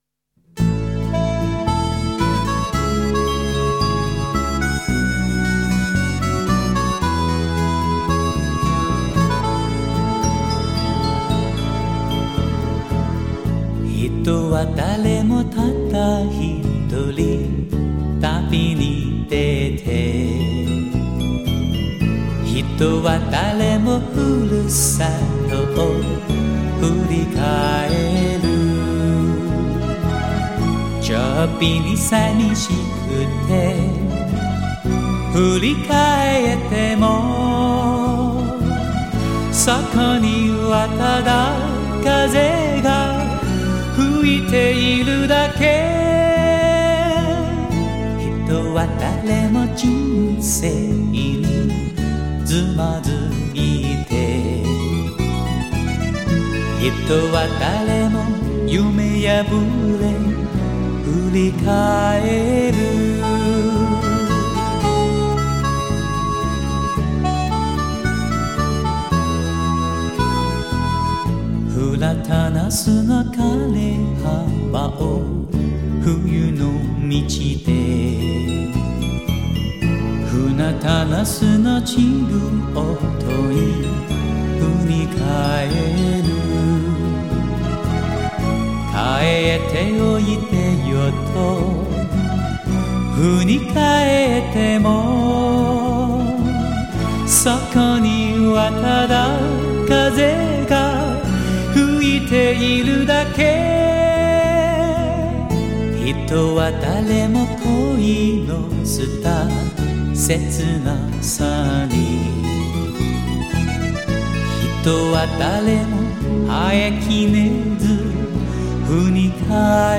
前所未有的人声超宽音域，融入超凡的乐器演奏技艺中，
达到人声、音乐与大自然融和整合，结聚成完美的“天籁之音”。